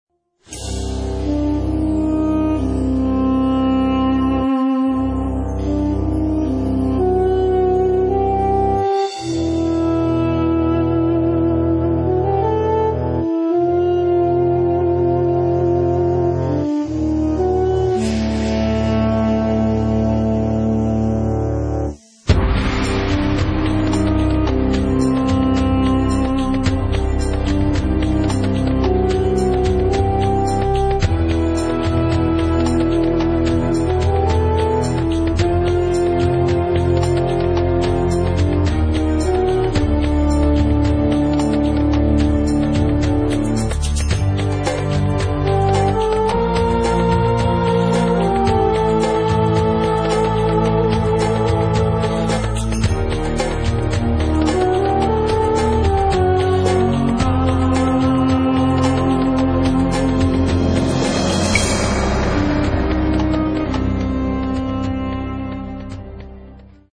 Film / Classical Examples